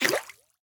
Minecraft Version Minecraft Version 25w18a Latest Release | Latest Snapshot 25w18a / assets / minecraft / sounds / mob / axolotl / attack1.ogg Compare With Compare With Latest Release | Latest Snapshot